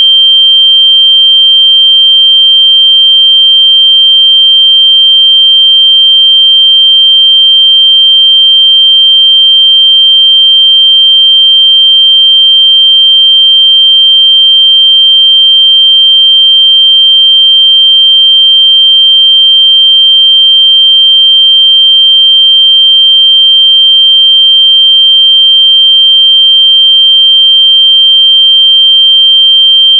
下面是用Multi-Instrument的信号发生器生成的30秒长的标准测试信号（WAV文件），可供下载。